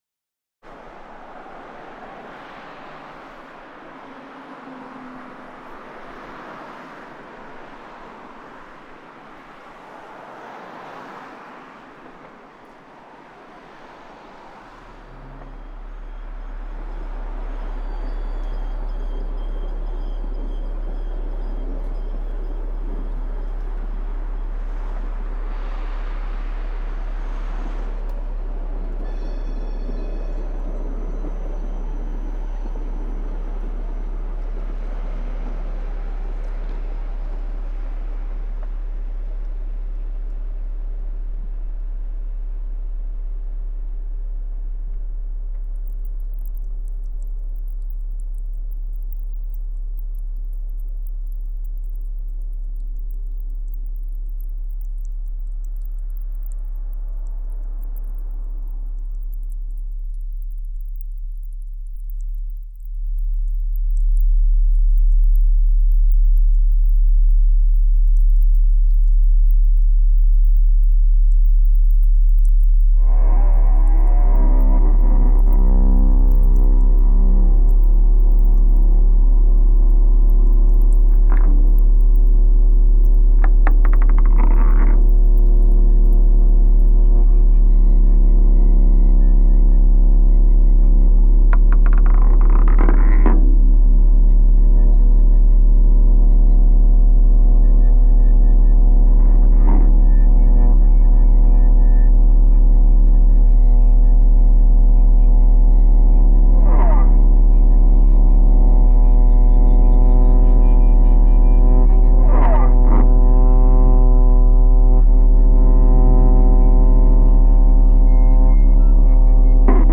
Kaukasische Flügelnuss - Stadtpark